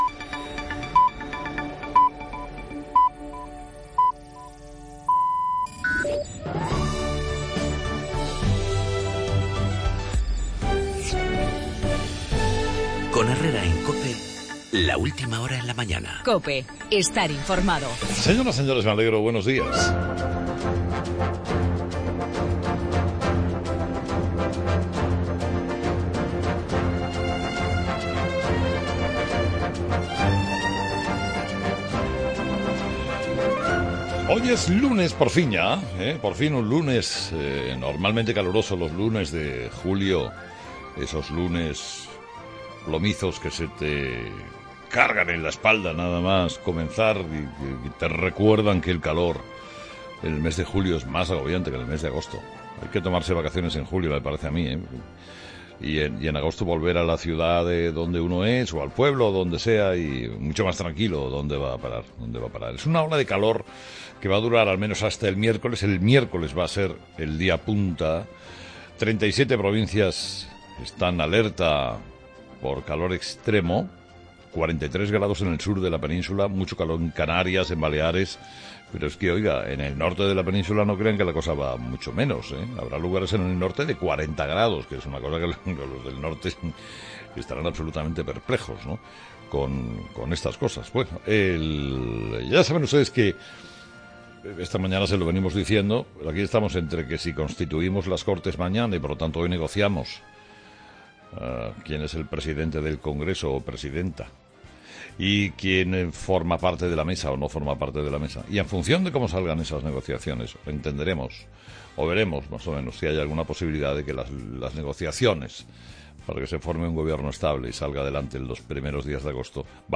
La reacción de Francia tras el atentado terrorista de Niza; el intento de golpe de Estado en Turquía, un país dividido ante el aprovechamiento de Erdogan para afianzar el poder; en el editorial de Carlos Herrera a las 8 de la mañana